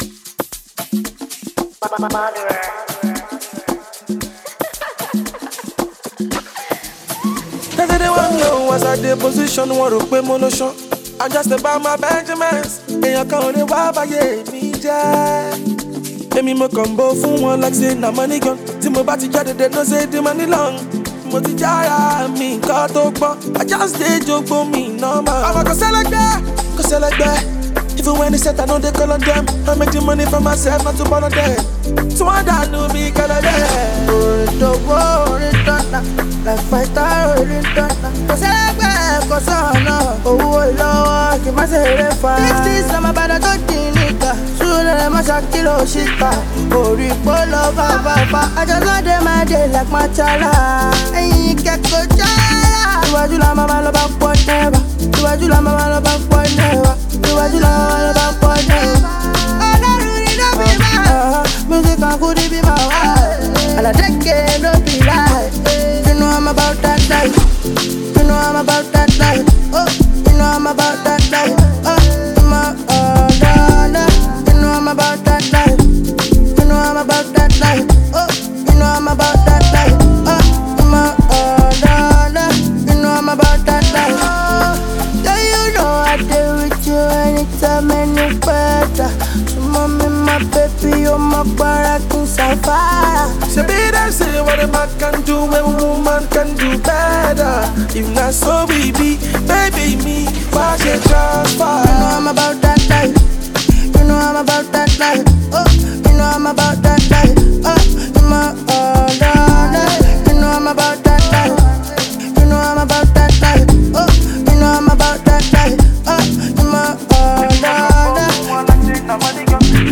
Melodic New Single
the soulful vocals